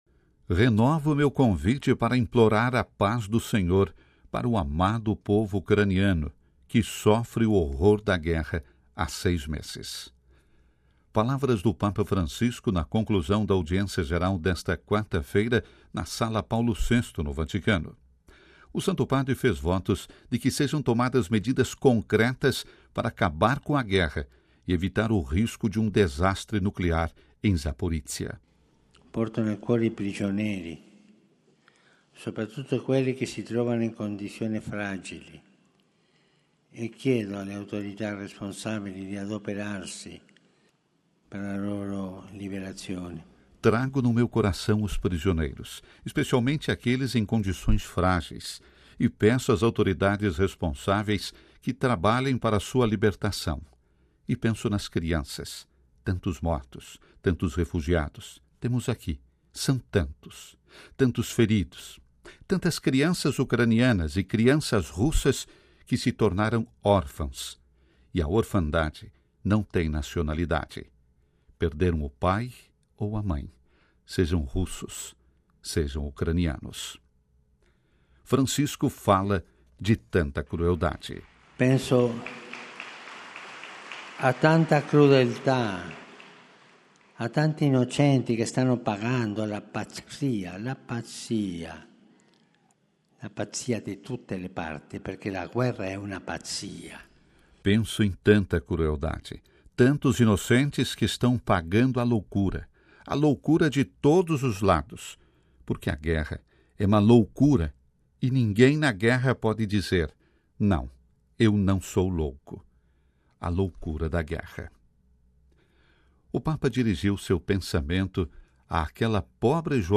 “Renovo meu convite para implorar a paz do Senhor para o amado povo ucraniano que sofre o horror da guerra há seis meses”: palavras do Papa Francisco na conclusão da Audiência geral desta quarta-feira na Sla Paulo VI, no Vaticano. O Santo Padre fez votos de que sejam tomadas medidas concretas para acabar com a guerra e evitar o risco de um desastre nuclear em Zaporizhzhia.
Papa Francisco durante a Audiência geral